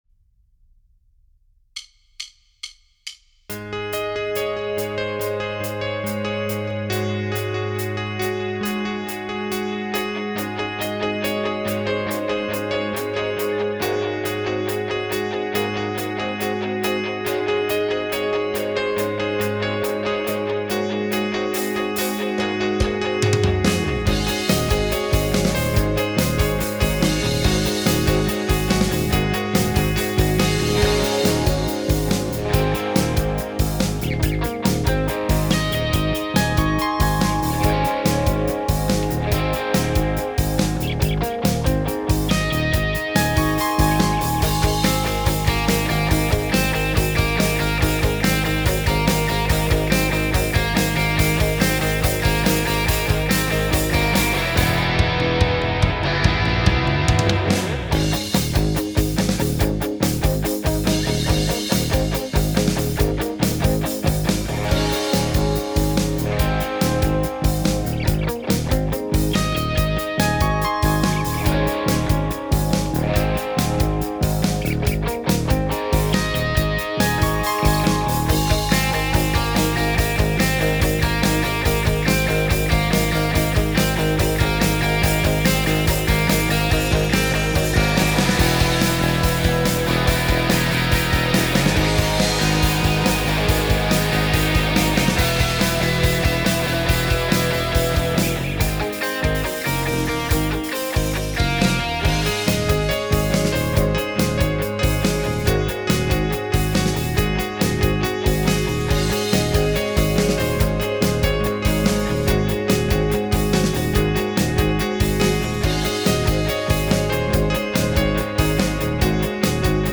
BPM : 141
Tuning : Eb
Without vocals